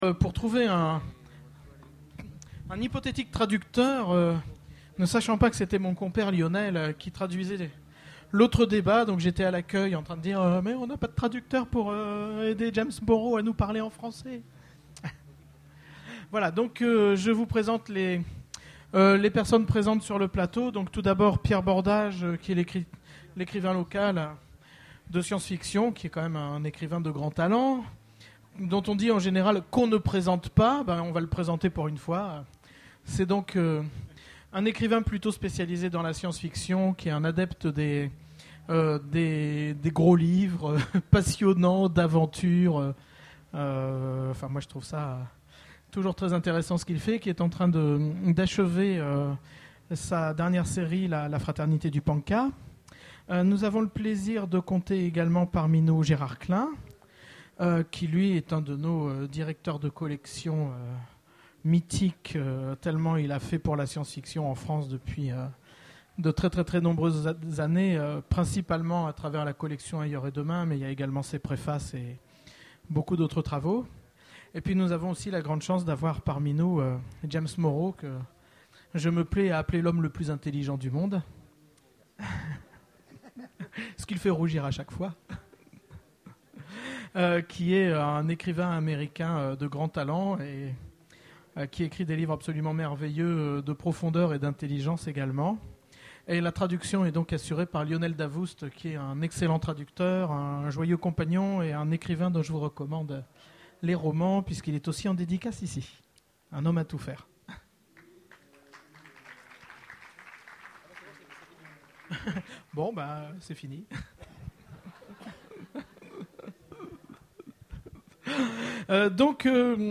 Utopiales 2011 : Conférence l'Histoire a-t-elle été écrite par les religions ?